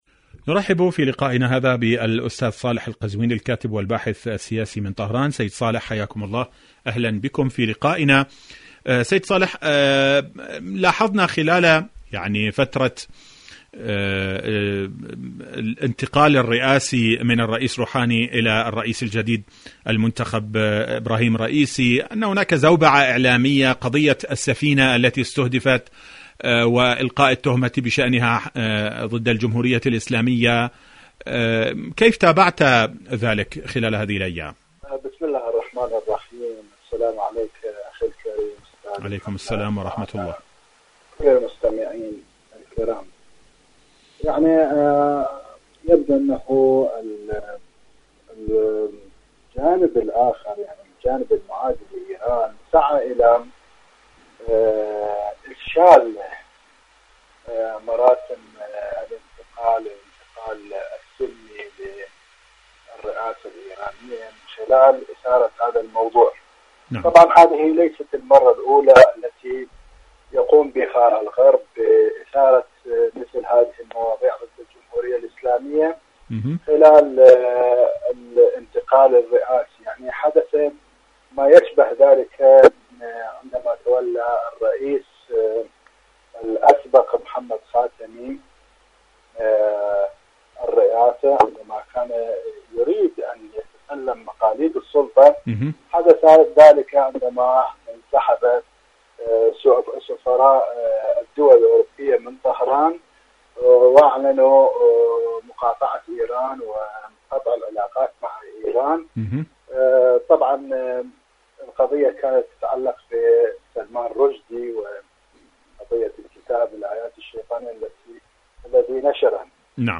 إذاعة طهران-إيران اليوم المشهد السياسي: مقابلة إذاعية